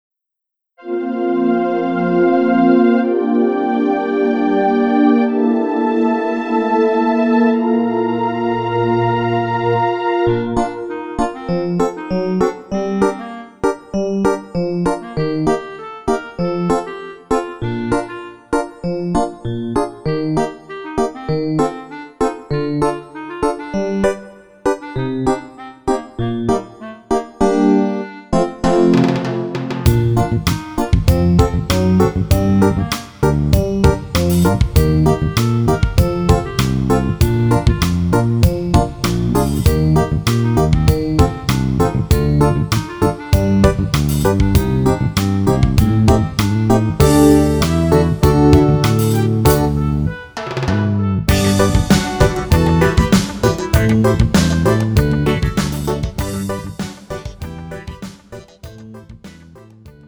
음정 -1키 3:42
장르 가요 구분 Pro MR